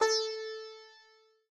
sitar_a.ogg